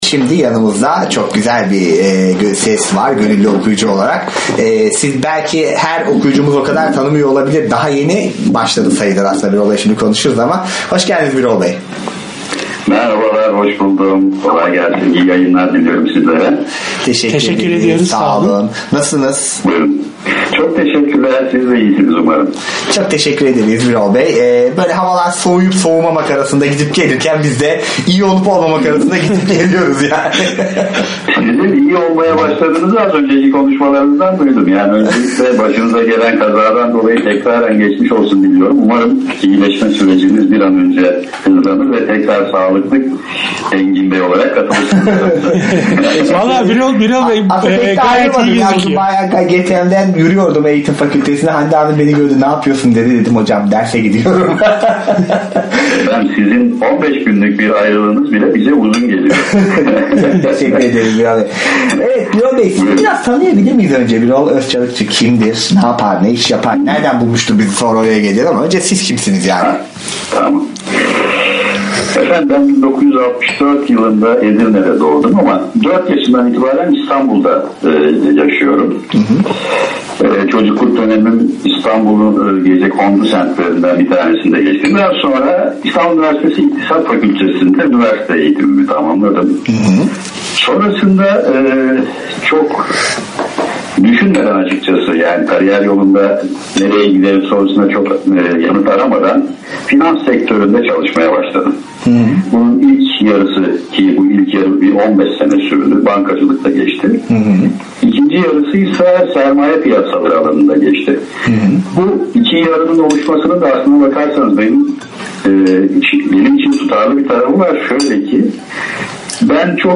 Gönüllü okuyucu röportajları Engelsiz Erişim Derneği